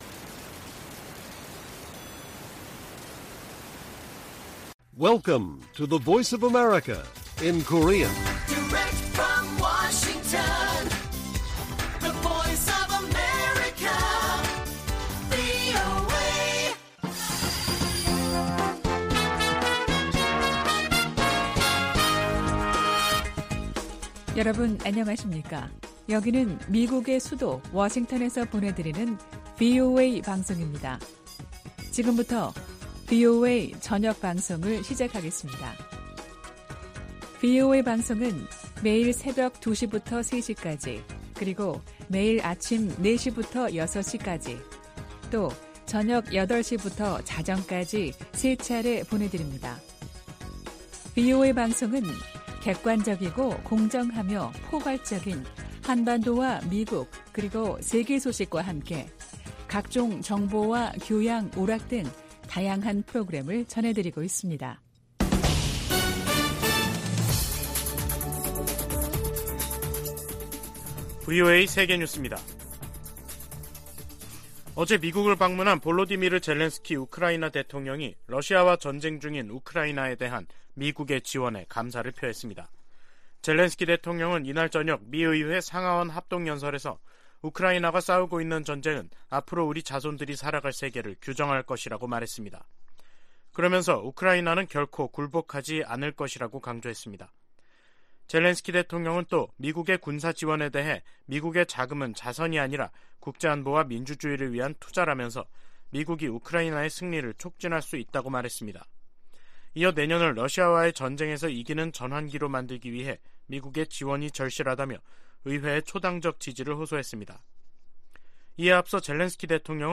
VOA 한국어 간판 뉴스 프로그램 '뉴스 투데이', 2022년 12월 22일 1부 방송입니다. 미국 의회는 2023회계연도 일괄 지출안에서 북한 관련 지출은 인권 증진과 대북 방송 활동에만 국한하도록 규정했습니다. 미국은 내년 아시아태평양경제협력체(APEC) 의장국으로서 경제적 지도력을 보여주고 역내의 경제 회복력 강화를 도모할 것이라고 밝혔습니다.